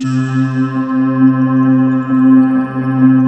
Index of /90_sSampleCDs/USB Soundscan vol.28 - Choir Acoustic & Synth [AKAI] 1CD/Partition C/16-NIMBUSSE